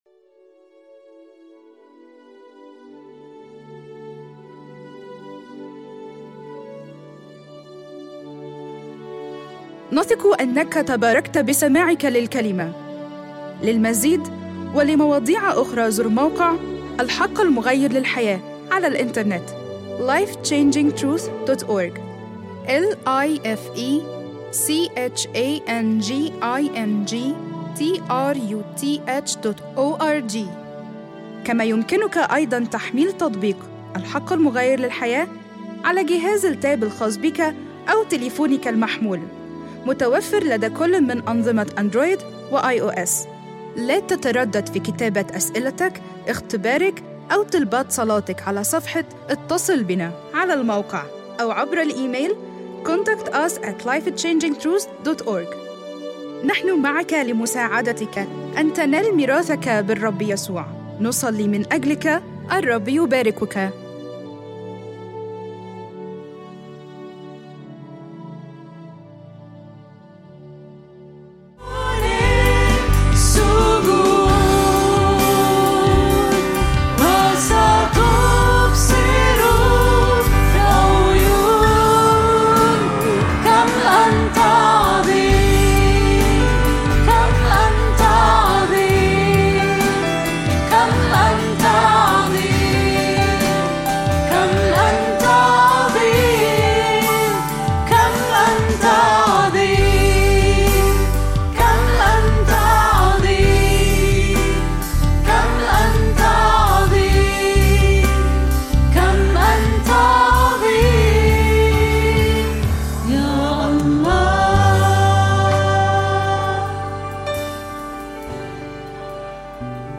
اجتماع الثلاثاء
العظة